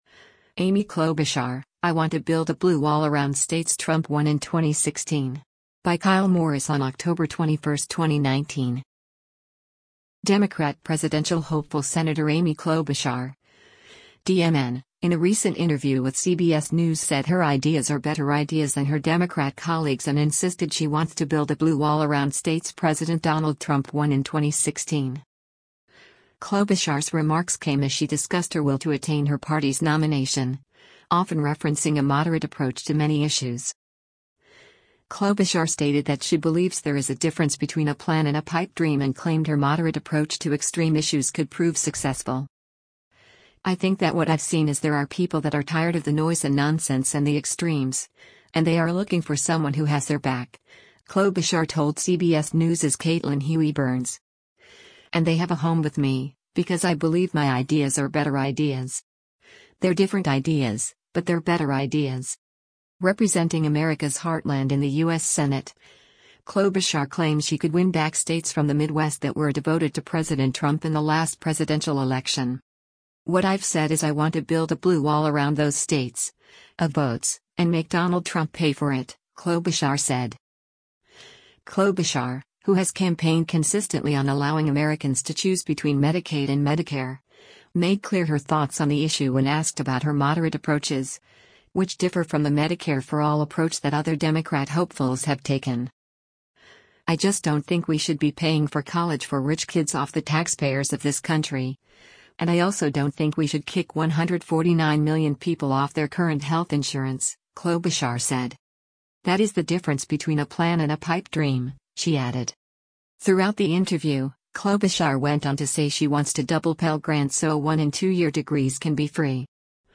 Democrat presidential hopeful Sen. Amy Klobuchar (D-MN) in a recent interview with CBS News said her “ideas are better ideas” than her Democrat colleagues and insisted she wants to “build a blue wall around” states President Donald Trump won in 2016.